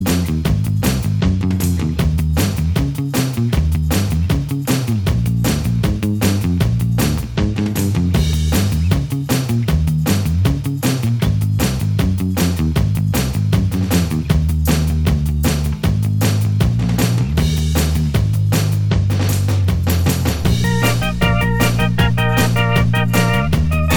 Minus Guitars Pop (1960s) 2:23 Buy £1.50